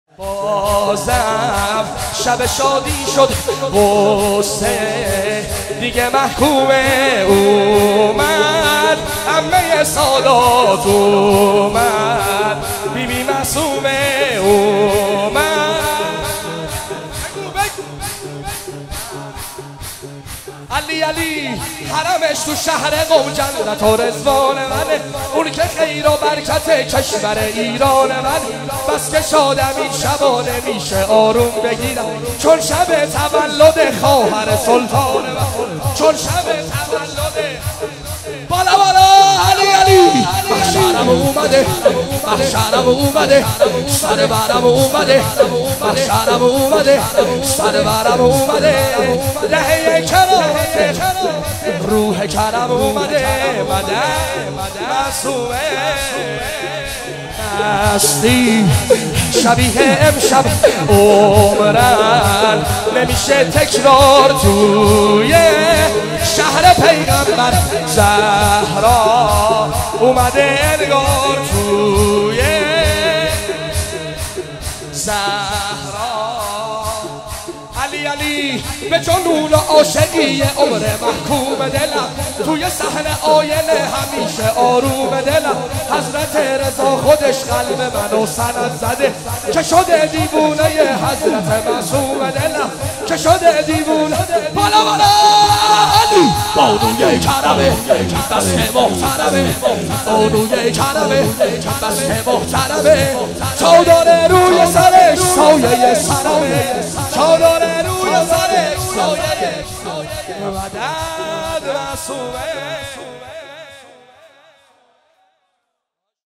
ولادت حضرت معصومه (س) هیئت مکتب الزهرا(س) قم